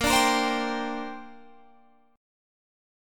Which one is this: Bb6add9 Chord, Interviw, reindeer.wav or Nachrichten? Bb6add9 Chord